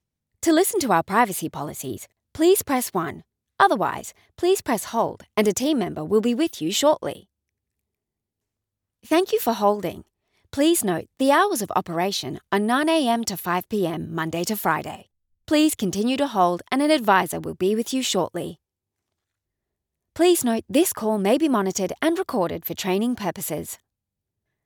English (Australia)
Natural, Playful, Reliable, Friendly, Warm
Telephony